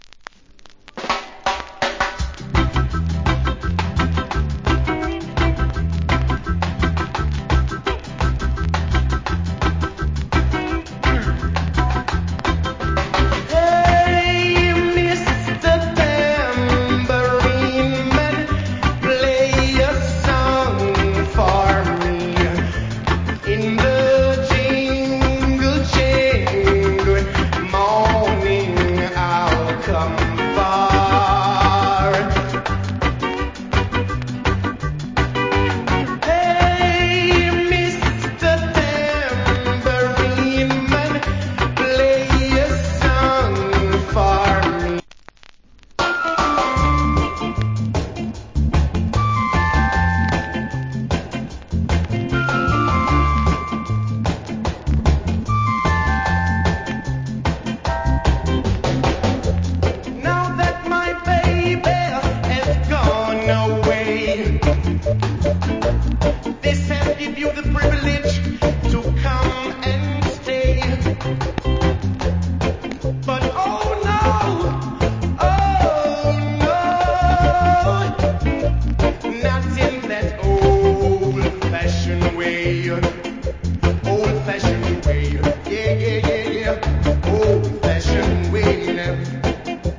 Nice Early Reggae Vocal.